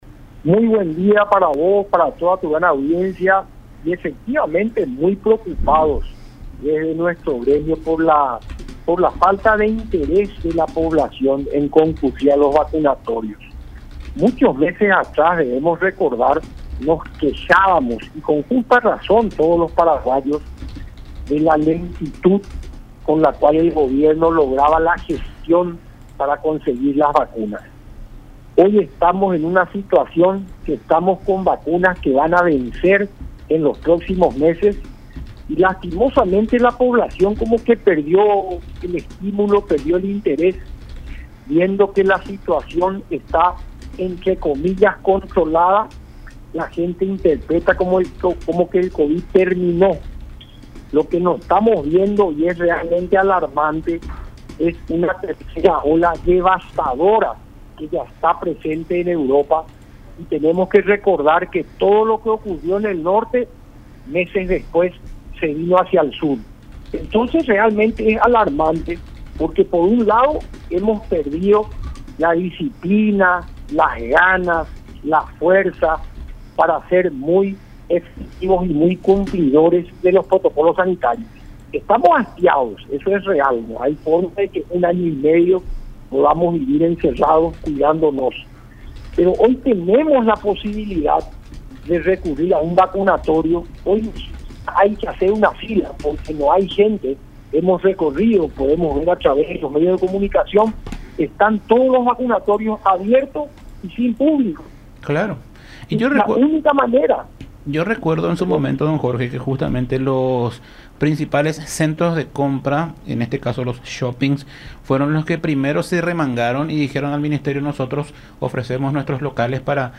en conversación con Todas Las Voces por La Unión.